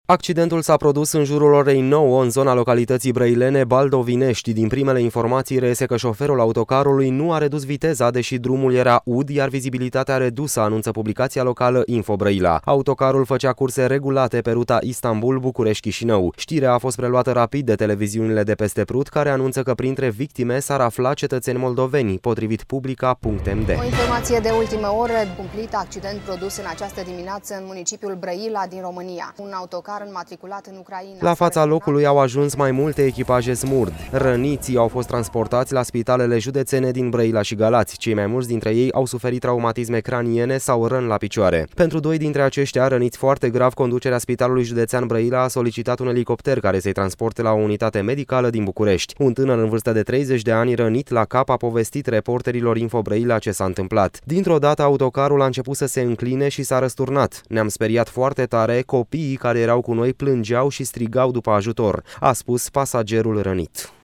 Un bărbat aflat în autocarul răsturnat în județul Brăila le-a povestit jurnaliștilor ce s-a întâmplat.